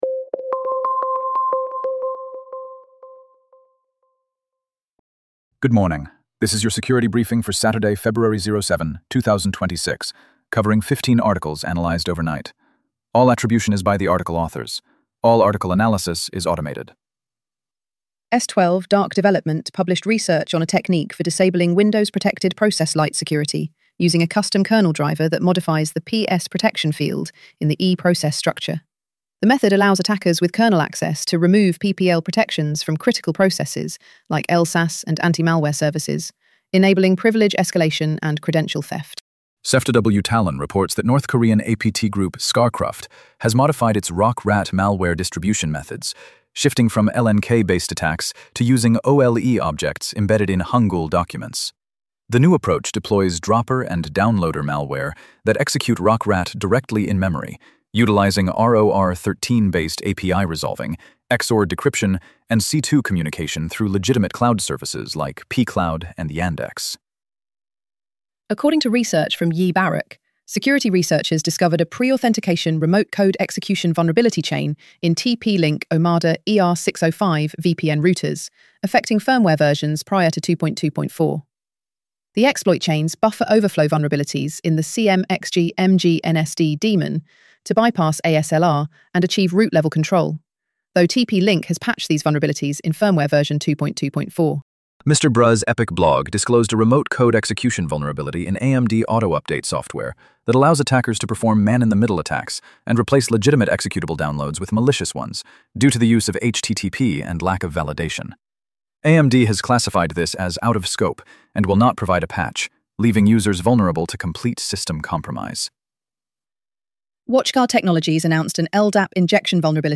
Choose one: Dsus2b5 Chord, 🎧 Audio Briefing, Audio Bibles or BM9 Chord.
🎧 Audio Briefing